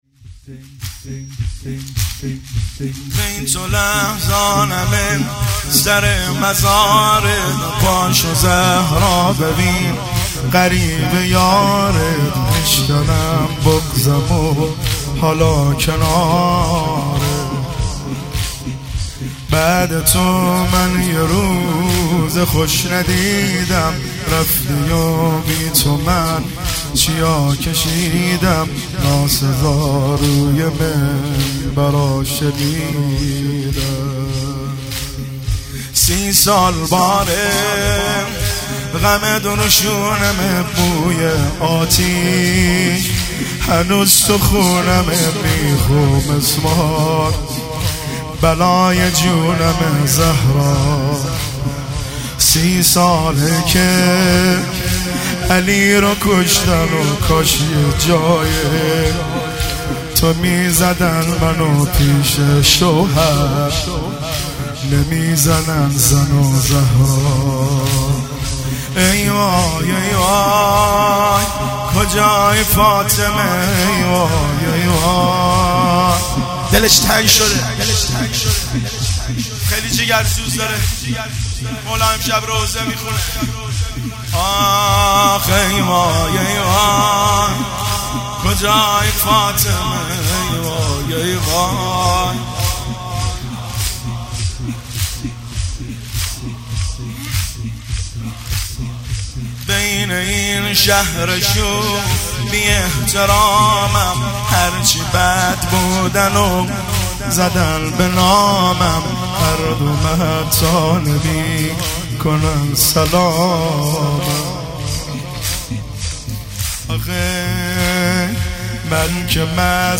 تک نوحه